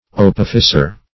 Search Result for " opificer" : The Collaborative International Dictionary of English v.0.48: Opificer \O*pif"i*cer\, n. An artificer; a workman.